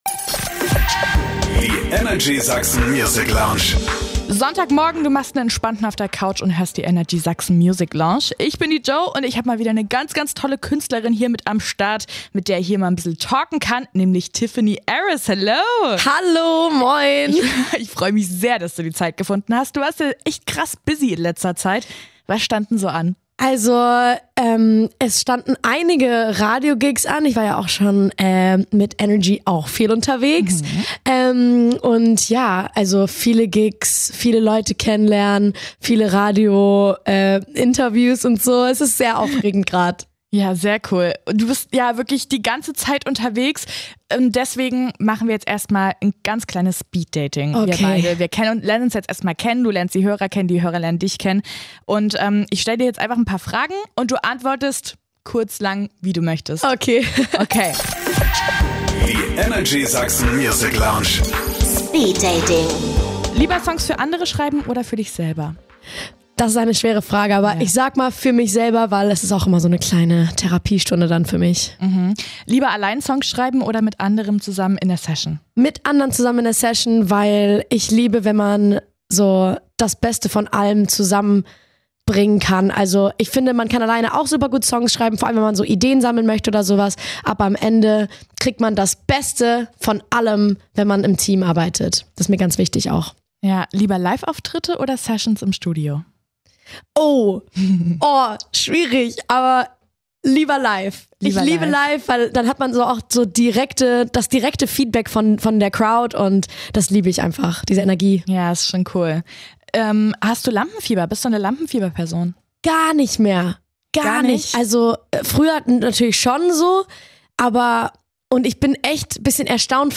Ein ehrliches Gespräch über mentale Gesundheit, Songwriting und prägende Menschen in ihrem Leben – eine mausige Runde zum Sonntag!